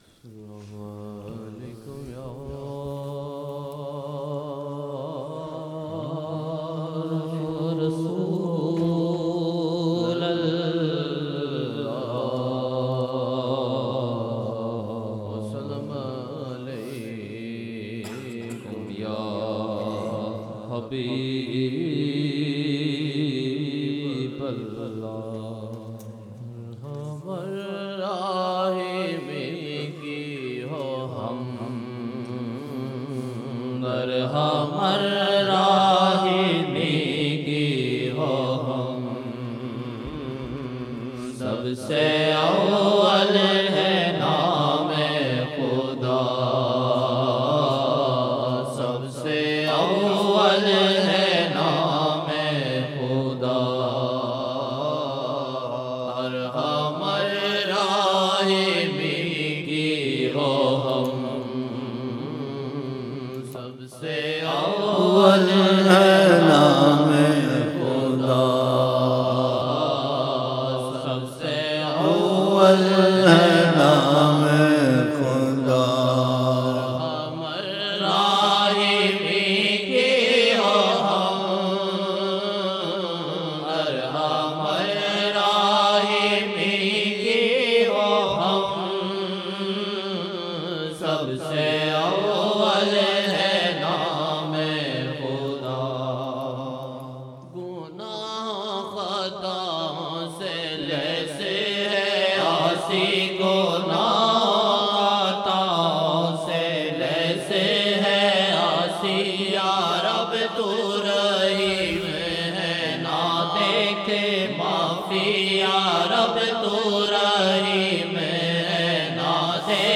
Naatia Ashaar
Naatia Ashaar 2007-01-06 Zohr 06 Jan 2007 Old Naat Shareef